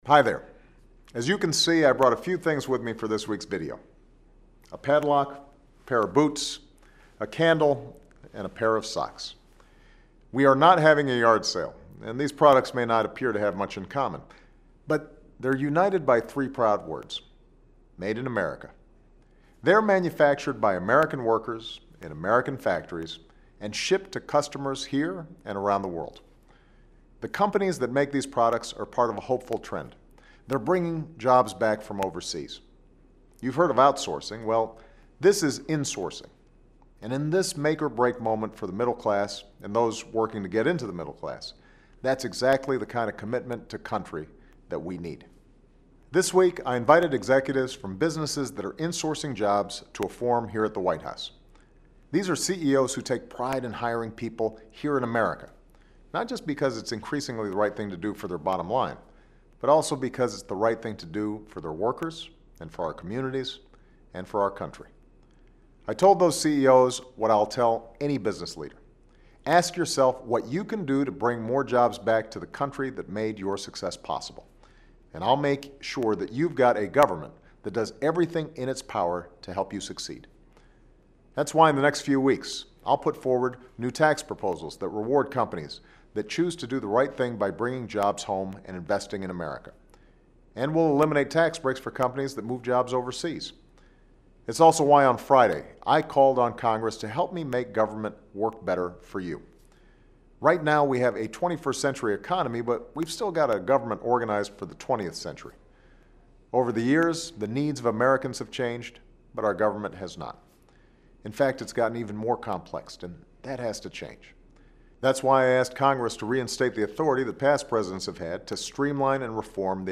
Remarks of President Barack Obama
Weekly Address